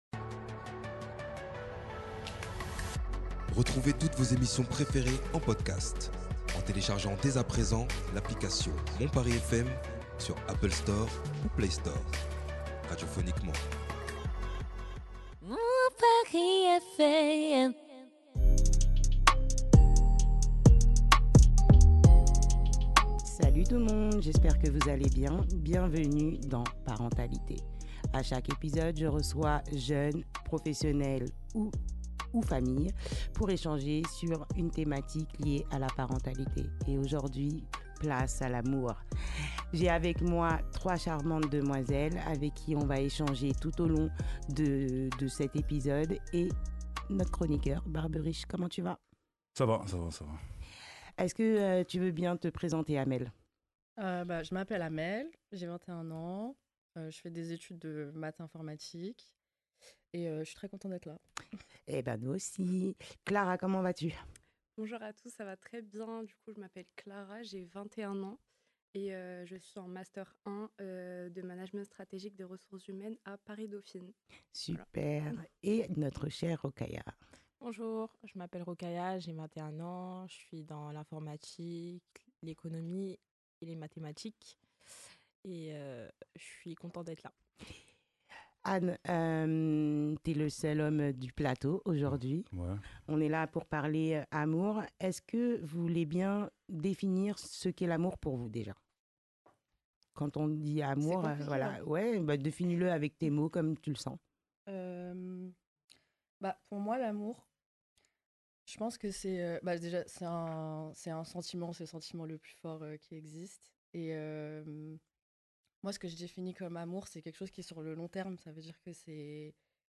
trois jeunes femmes de 21 ans